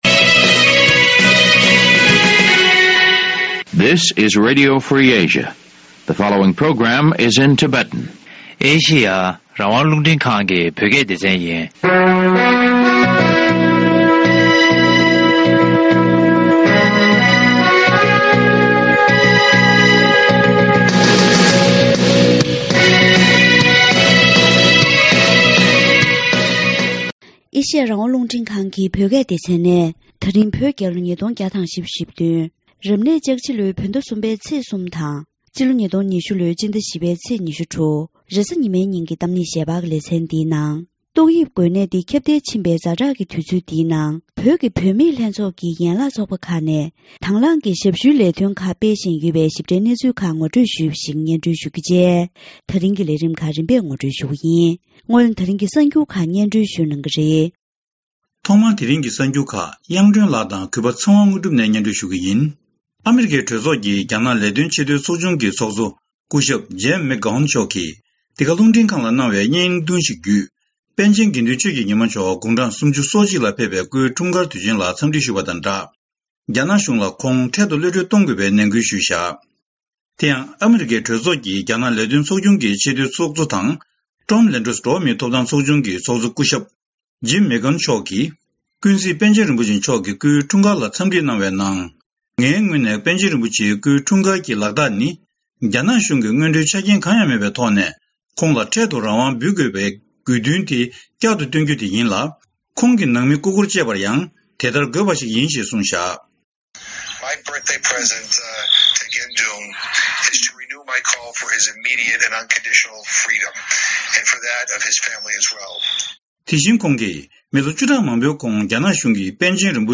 འབྲེལ་ཡོད་ཁག་ཅིག་ལ་བཅར་འདྲི་ཞུས་པ